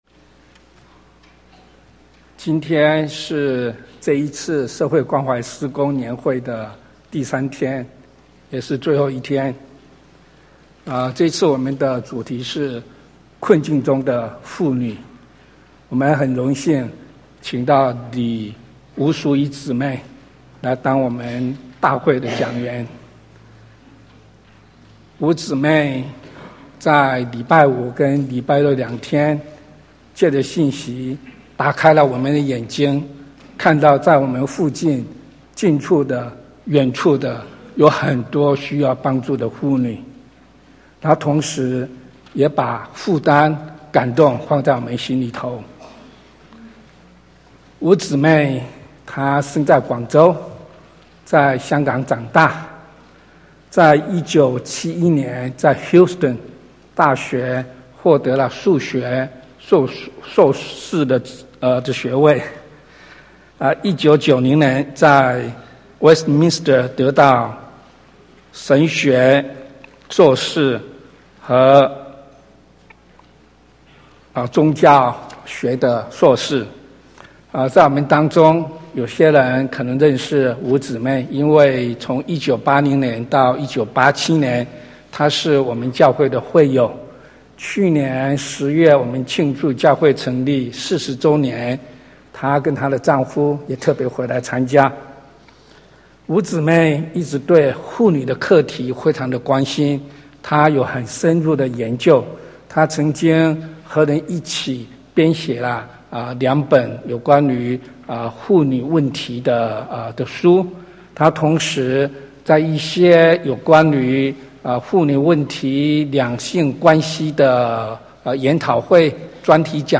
Sermon | CBCGB